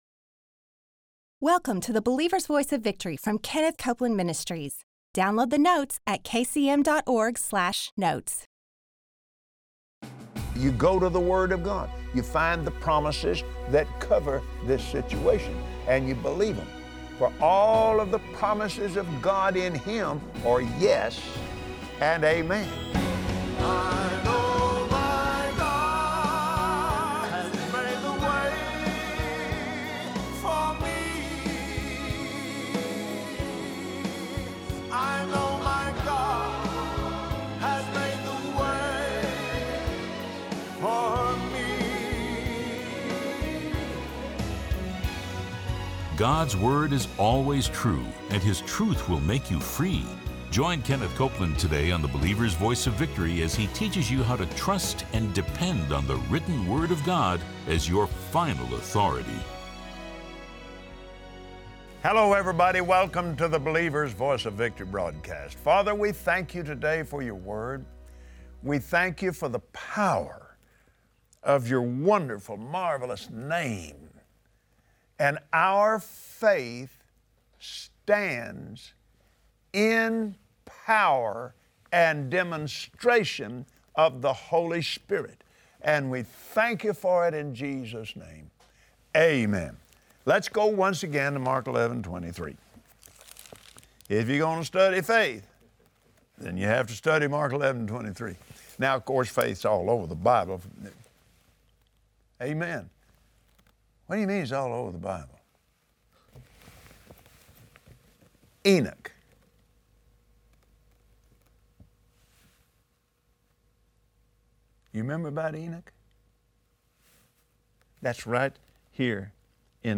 Watch Kenneth Copeland on Believer’s Voice of Victory explain how putting your faith in the truth of God’s WORD will always bring His power on the scene of your life.